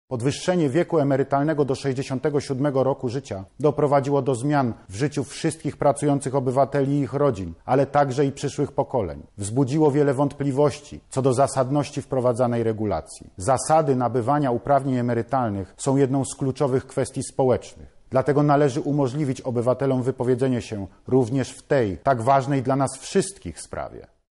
Ogłosił to podczas wieczornego orędzia do obywateli.